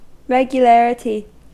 Ääntäminen
Ääntäminen US Tuntematon aksentti: IPA : /ˌɹɛɡjuˈlæɹəti/ Haettu sana löytyi näillä lähdekielillä: englanti Käännöksiä ei löytynyt valitulle kohdekielelle.